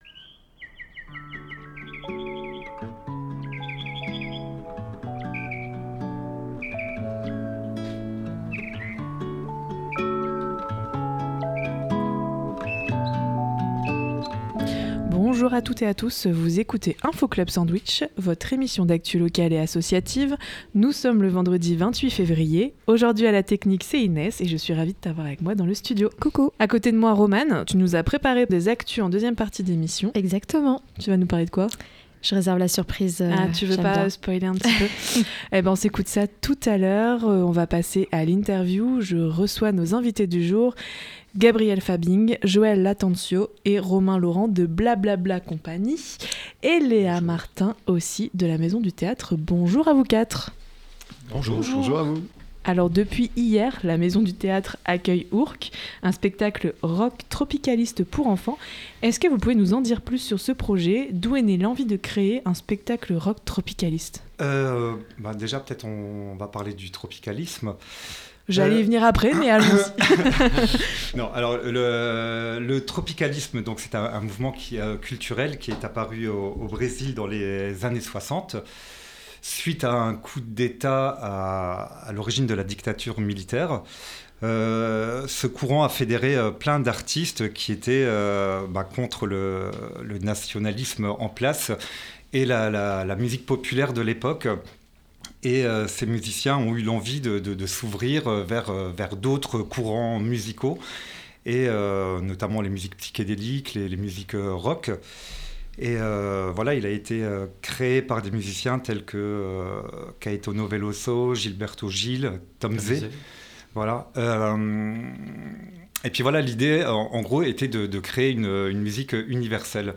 Côté musiques, on s’écoute